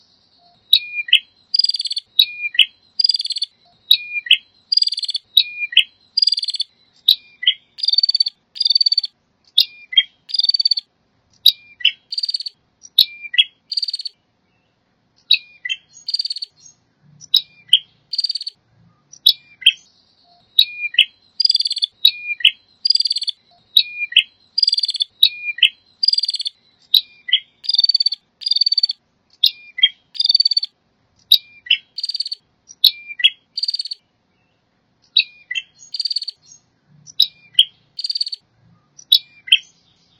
Mp3 Suara Burung Prenjak Jantan [Masteran]
> Suara Burung Prenjak Gacor